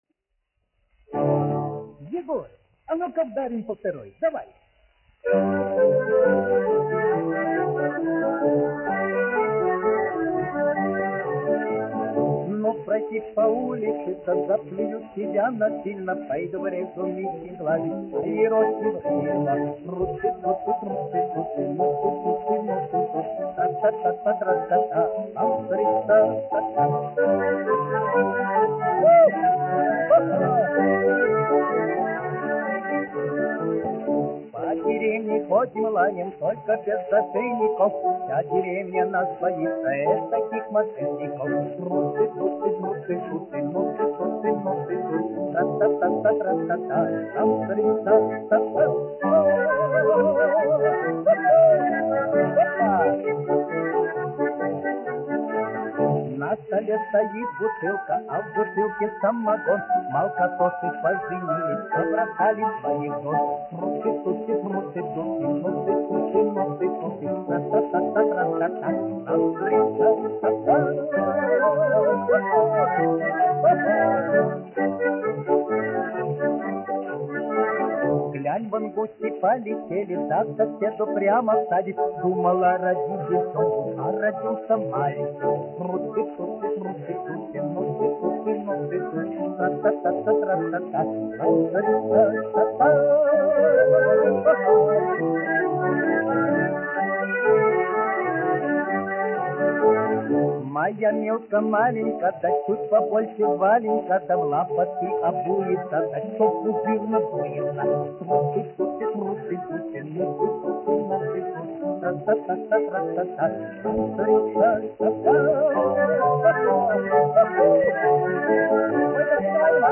1 skpl. : analogs, 78 apgr/min, mono ; 25 cm
Častuškas
Krievu tautasdziesmas
Skaņuplate